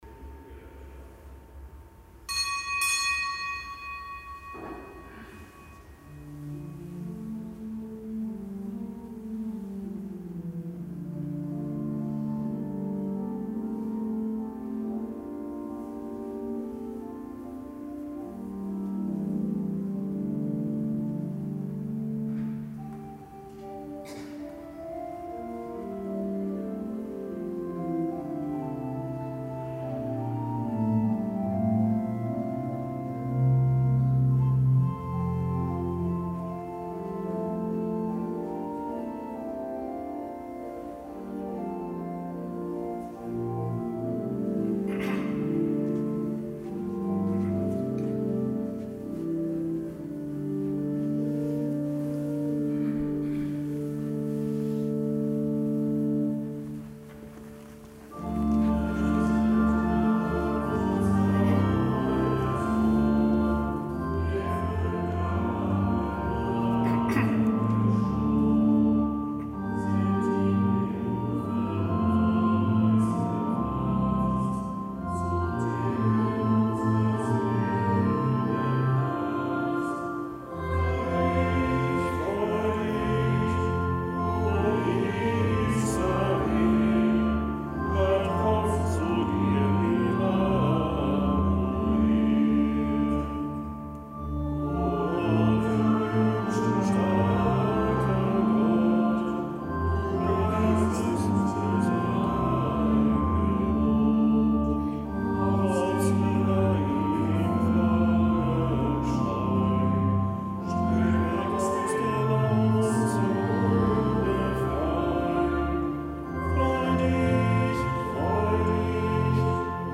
Kapitelsmesse aus dem Kölner Dom am Donnerstag der dritten Adventswoche; Zelebrant: Weihbischof Ansgar Puff.